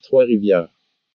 Trois-Rivières (French: [tʁwɑ ʁivjɛʁ], Canadian French: [tʁwɔ ʁivjaɛ̯ʁ]